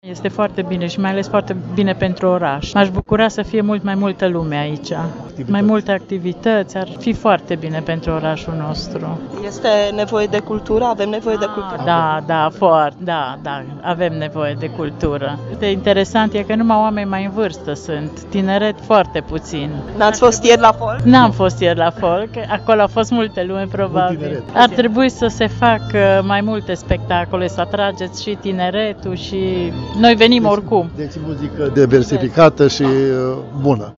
Impresii spectatori:
Reportaj-de-atmosfera-sala.mp3